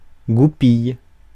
Ääntäminen
France: IPA: [gu.pij]